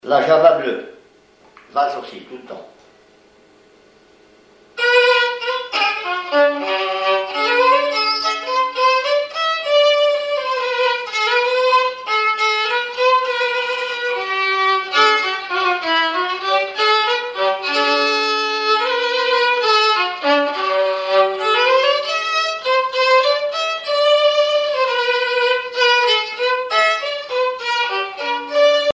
violoneux, violon
valse musette
Pièce musicale inédite